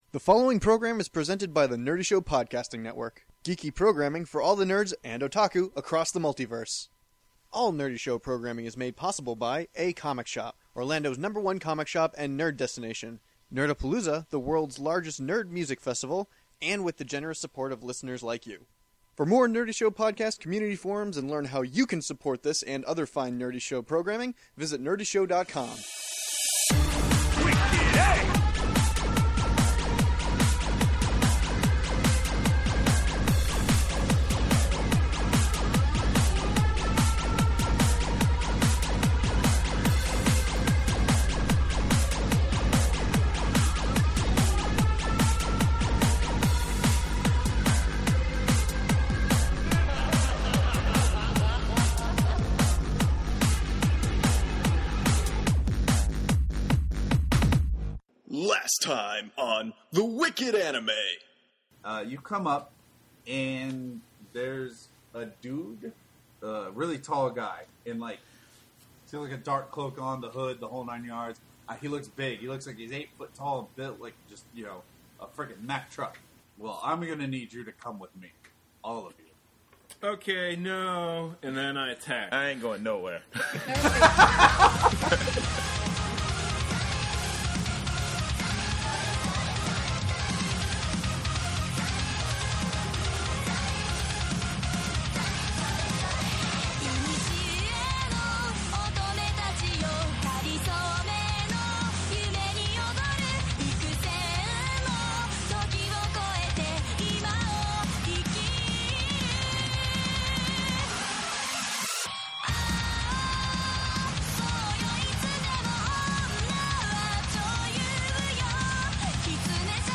Wicked Anime is playing the anime table top RPG BESM aka Big Eyes Small Mouth.